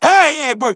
synthetic-wakewords
synthetic-wakewords / hey_eggbert /ovos-tts-plugin-deepponies_Franklin_en.wav
ovos-tts-plugin-deepponies_Franklin_en.wav